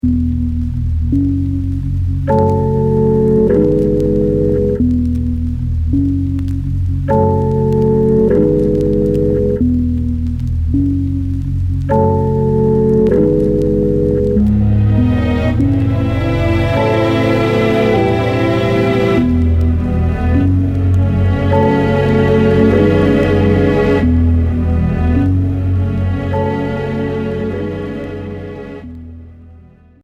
Trip-hop Premier 45t retour à l'accueil